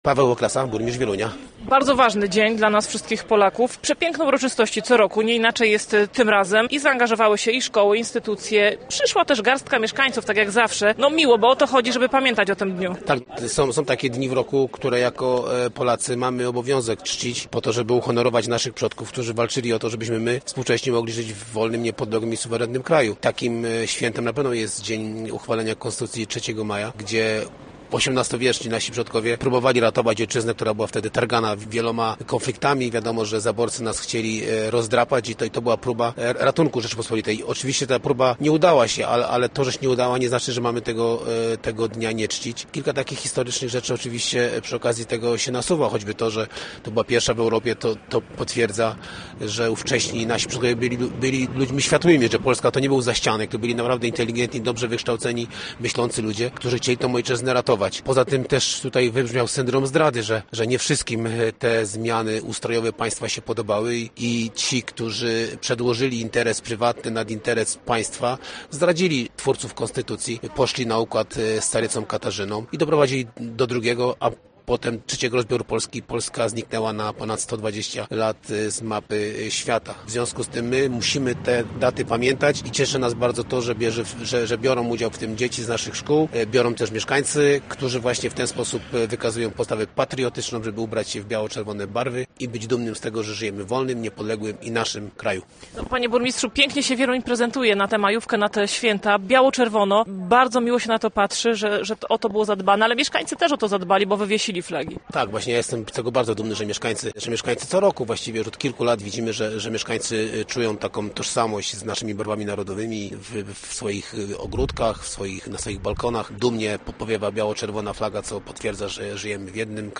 Gościem Radia ZW był burmistrz Wielunia Paweł Okrasa